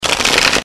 洗牌音效.MP3